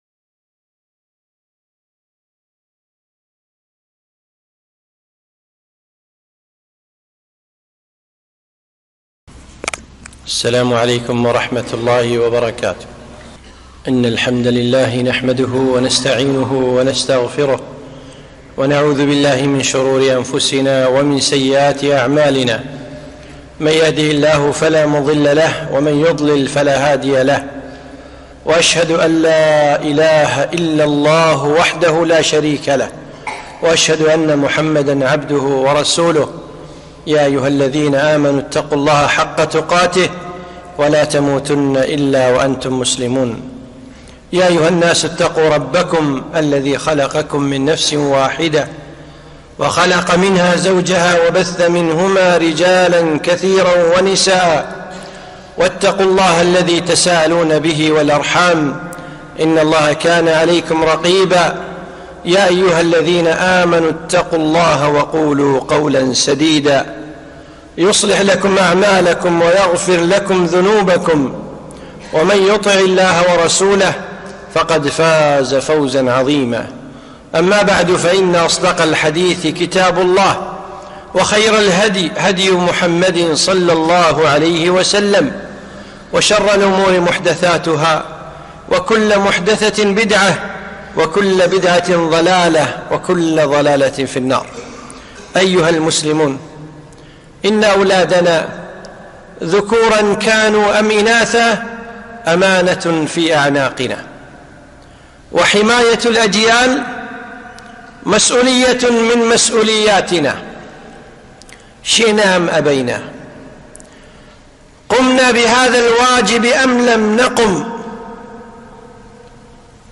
خطبة - حماية الجيل